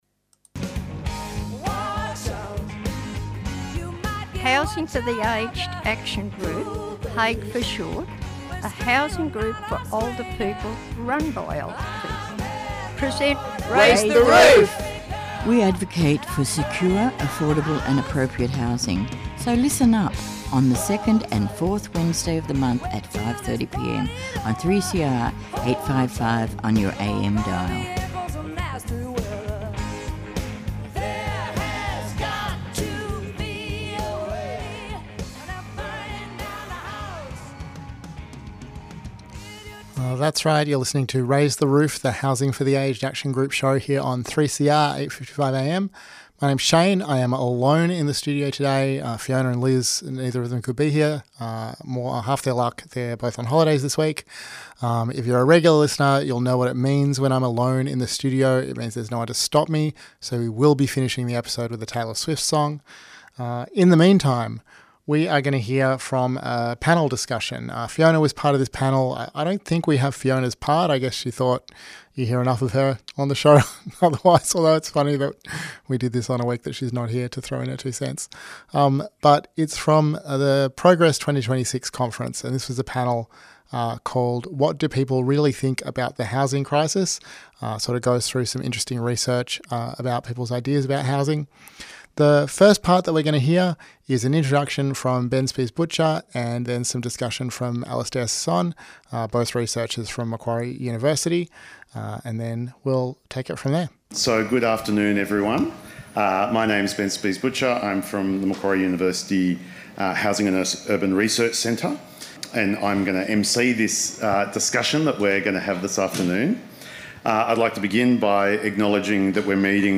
This episode we hear edited highlights from the panel 'What do people really think about the housing crisis?' from the Progress 2026 conference, with speakers including